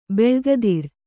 ♪ beḷgadir